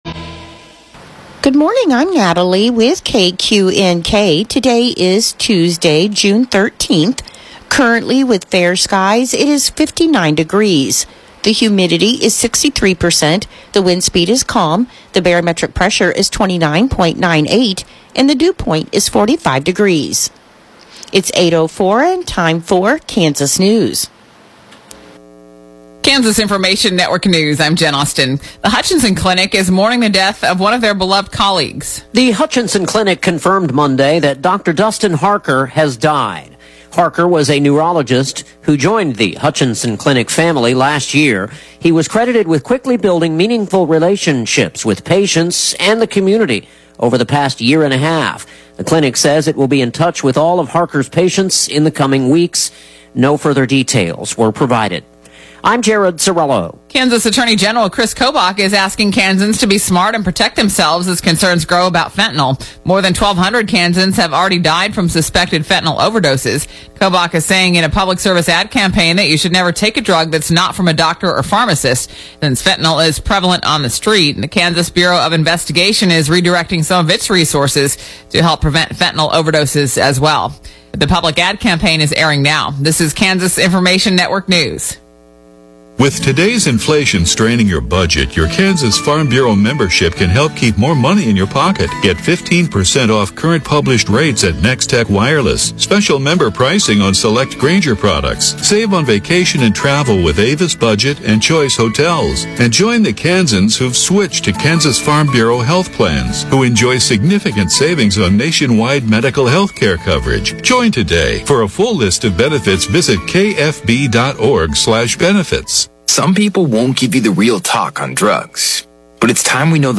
The KQNK Morning News podcast gives you local, regional, and state news as well as relevant information for your farm or home as well. Broadcasts are archived daily as originally broadcast on Classic Hits 106.7 KQNK-FM.